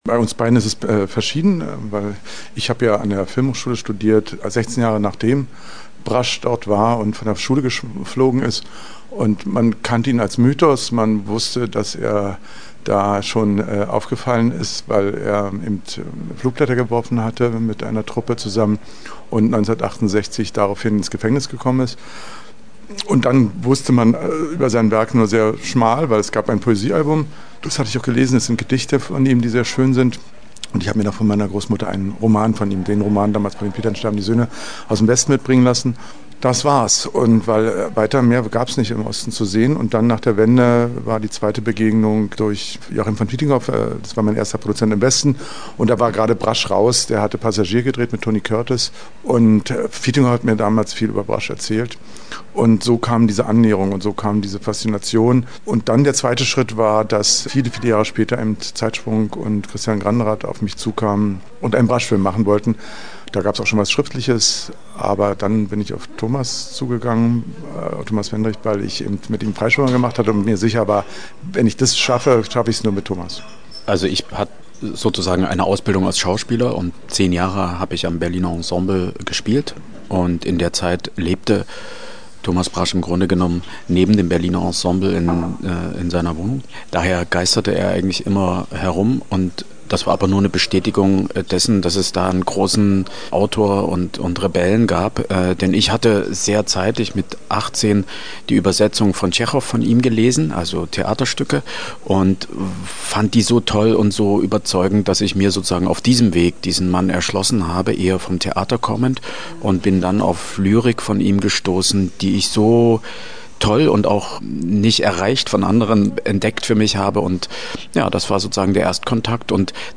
Filmgespräch